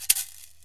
SNAPKIN PERC.wav